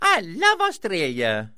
Mario saying "I love Australia!"